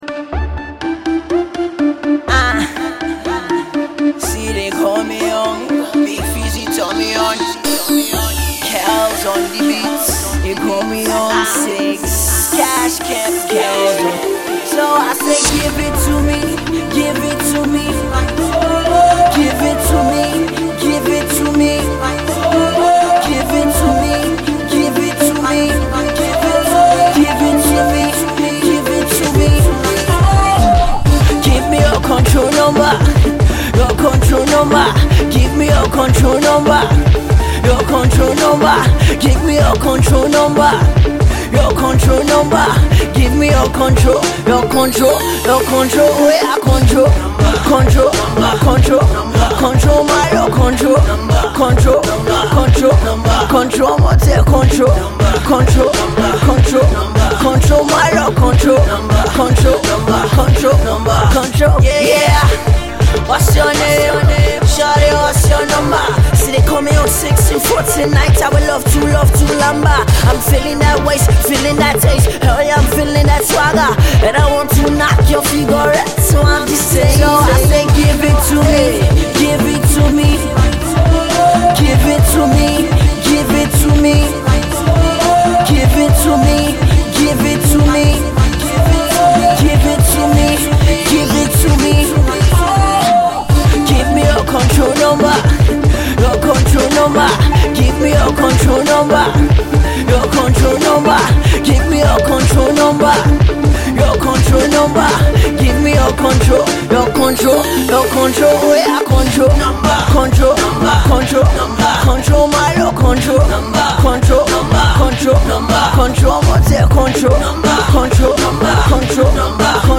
a master of entertaining wordplay